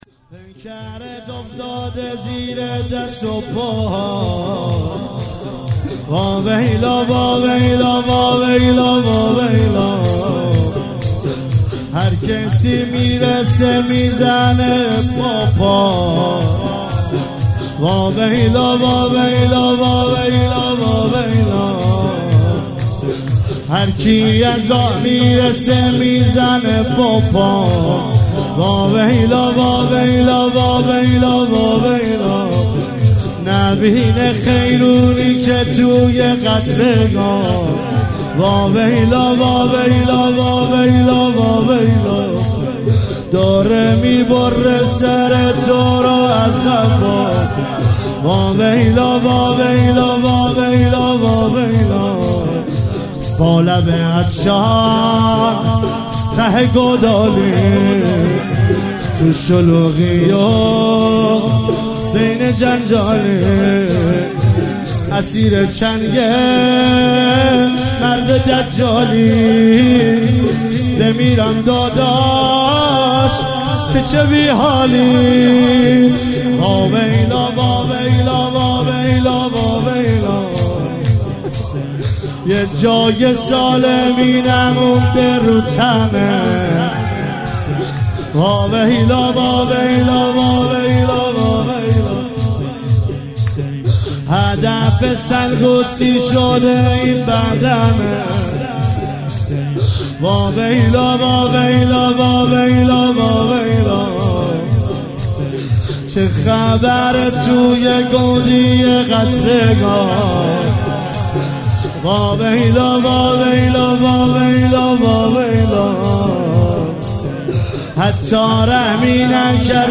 هفتگی 11 بهمن 96 - شور - پیکرت افتاده زیر دست و پا